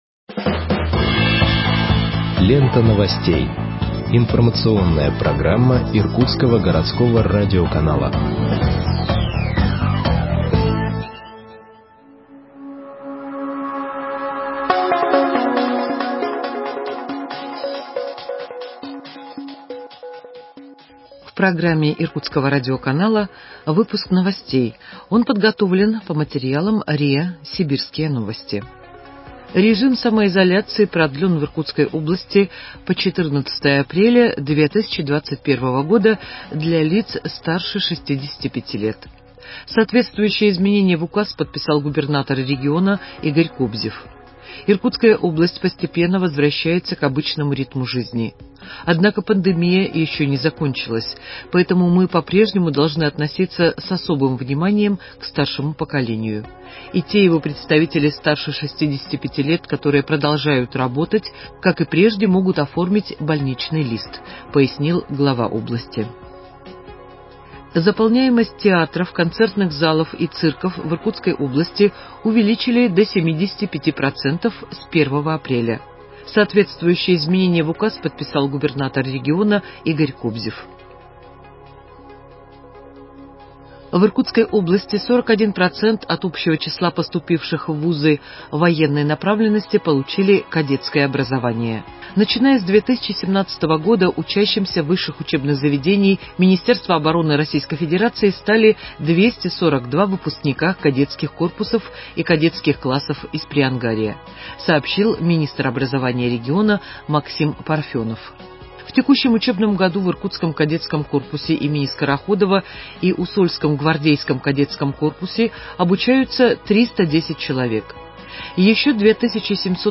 Выпуск новостей в подкастах газеты Иркутск от 02.04.2021 № 1